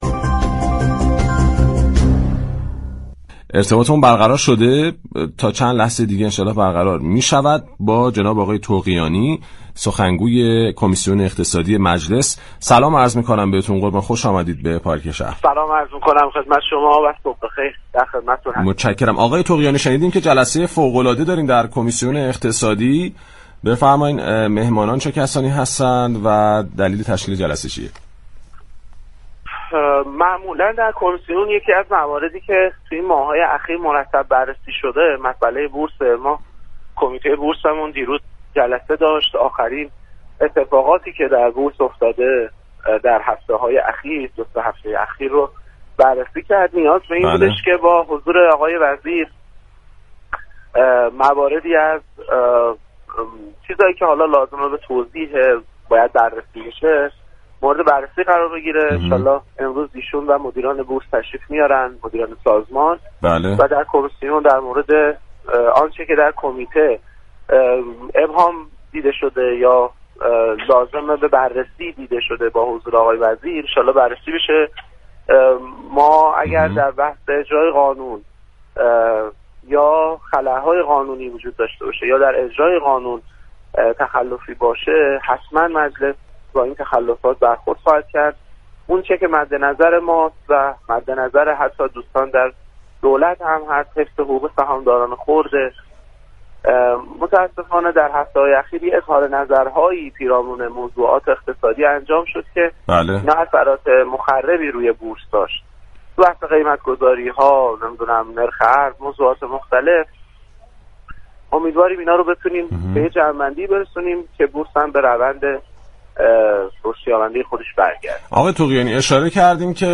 به گزارش پایگاه اطلاع رسانی رادیو تهران، مهدی طغیانی در گفتگو با برنامه پارك شهر با اشاره به اتفاقات اخیر در بورس و بررسی های كمیسیون اقتصادی درباره این موضوع گفت: با توجه به اتفاقات اخیر در بورس وزیر اقتصاد و مسئولان بورس امروز(سه شنبه 30 دیماه) در مجلس شورای اسلامی حضور می یابند و قرار است مسائل مختلف در این حوزه مورد بررسی قرار گیرد.